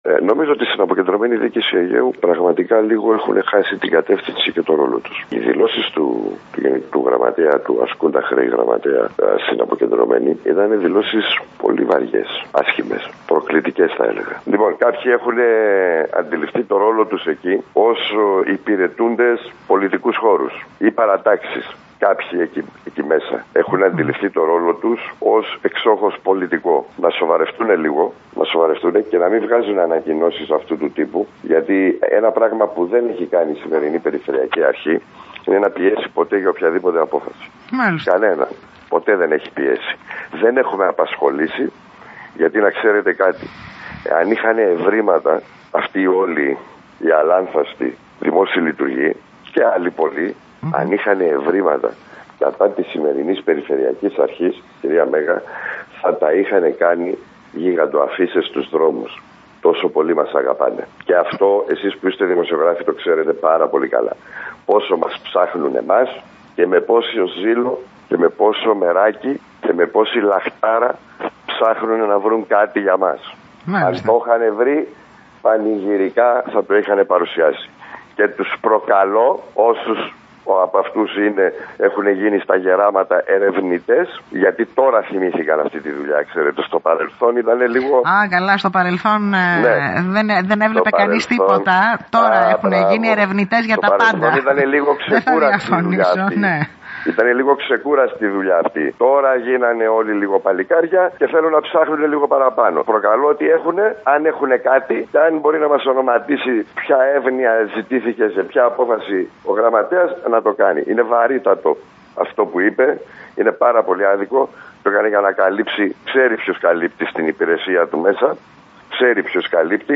Αυτό δήλωσε σήμερα, μιλώντας στην ΕΡΤ Ρόδου, ο περιφερειάρχης Νοτίου Αιγαίου Γιώργος Χατζημάρκος.
Ο Περιφερειάρχης Νοτίου Αιγαίου Γιώργος Χατζημάρκος, δήλωσε στην ΕΡΤ Ρόδου [Ηχητικό]